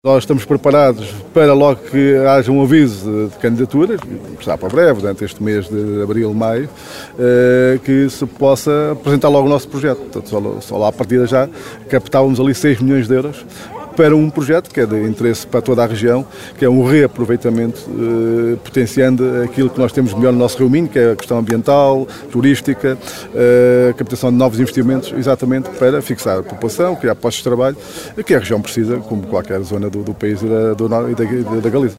Os signatários vão agora apresentar uma candidatura conjunta aos novos fundos comunitários, no valor de seis milhões de euros, para investir nas potencialidades daquele curso internacional, como avançou o autarca de Valença, Jorge Mendes.